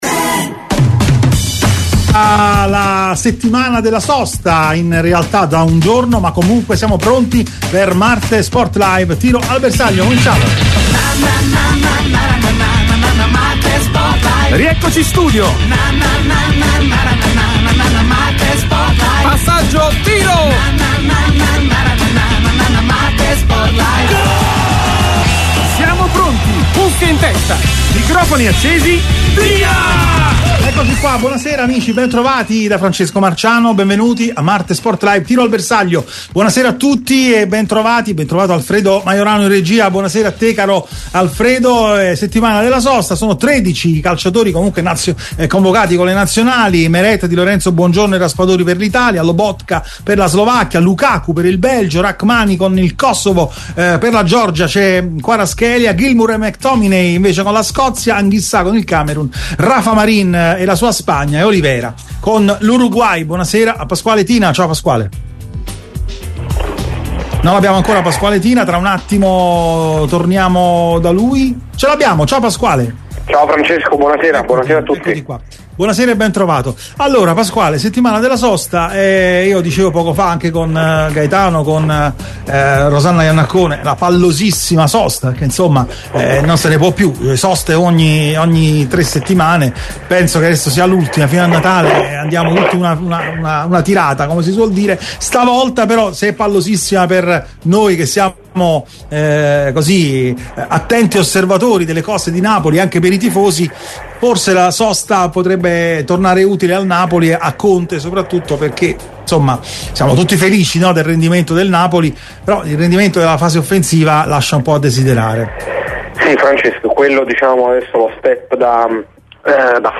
MARTE SPORT LIVE è UNA TRASMISSIONE SPORTIVA, UN TALK CON OSPITI PRESTIGIOSI, OPINIONISTI COMPETENTI, EX TECNICI E GIOCATORI DI VALORE, GIORNALISTI IN CARRIERA E PROTAGONISTI DEL CALCIO ITALIANO E INTERNAZIONALE.